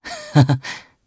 Theo-Vox_Happy3_jp.wav